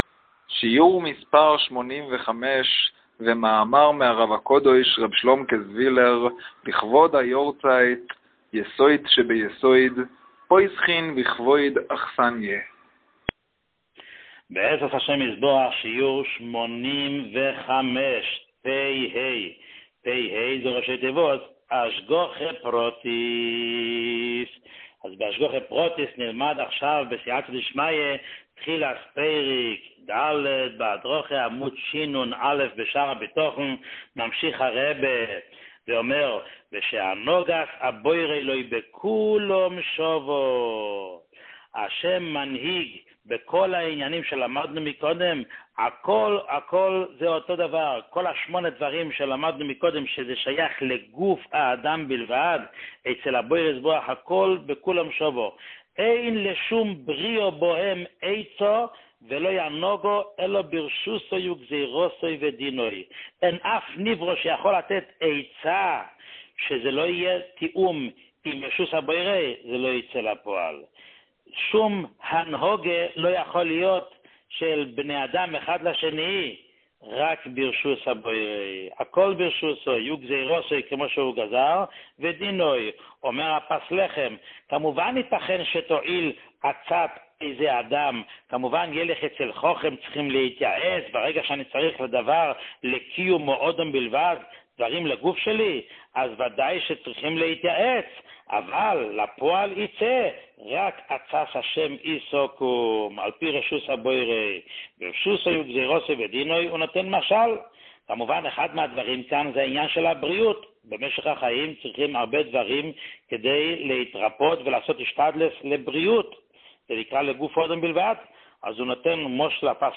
שיעור 85